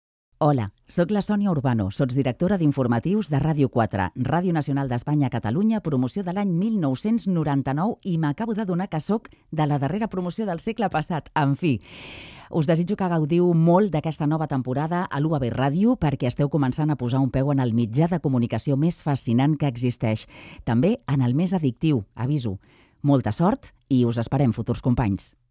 Salutació amb motiu de l'inici de la temporada radiofònica d'UAB Ràdio 2023-2024